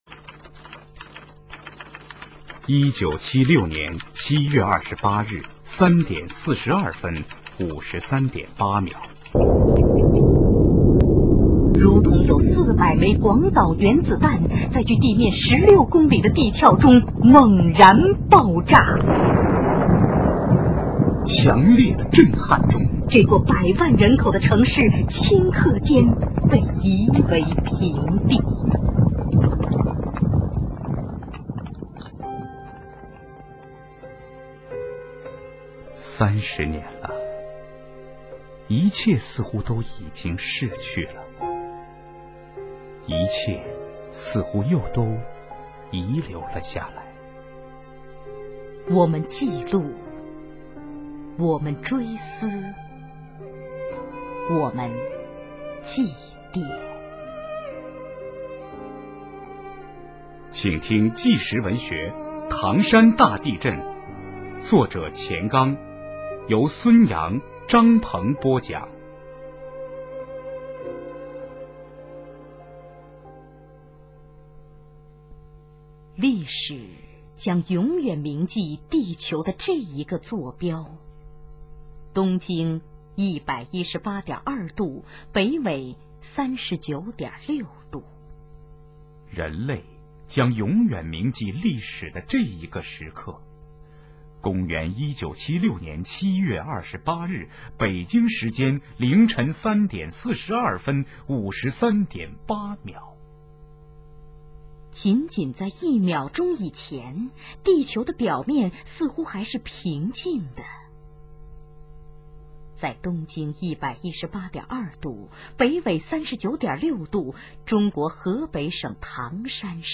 资源类型: 小说连播